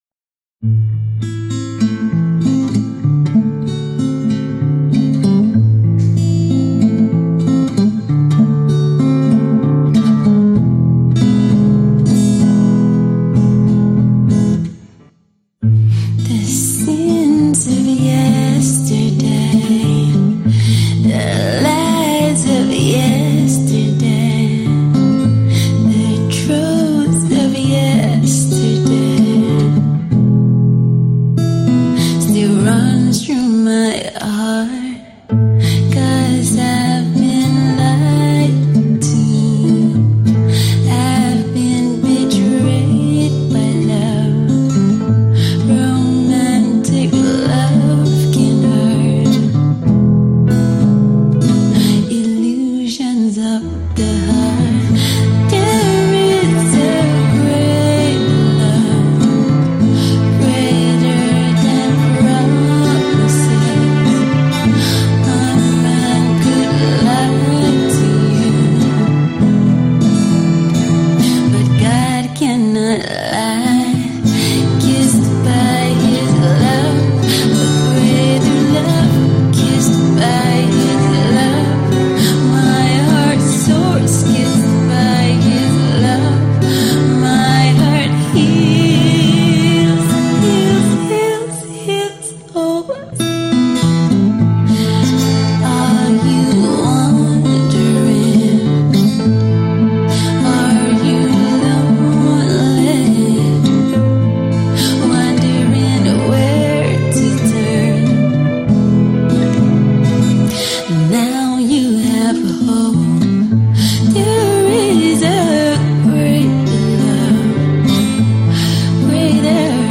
an acoustic ballad reminiscent of the likes of Asa